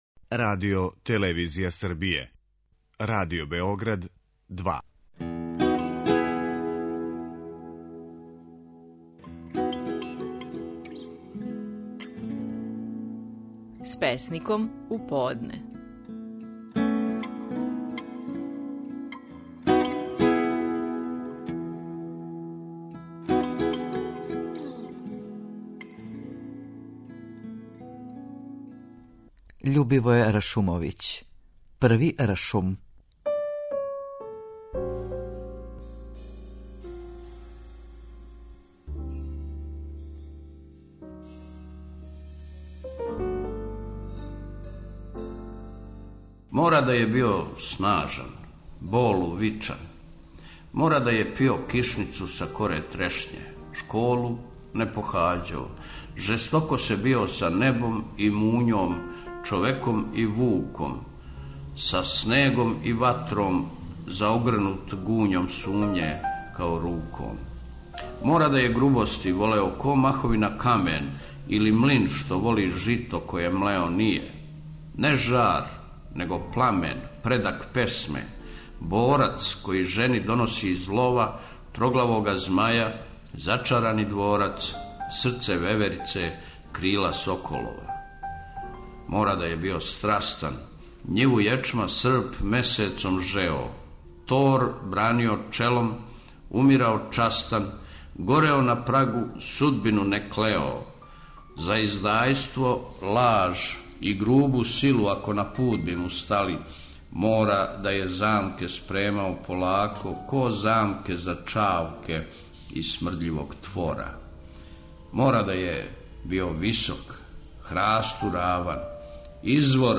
Наши најпознатији песници говоре своје стихове
Љубивоје Ршумовић говори своју песму „Први Ршум".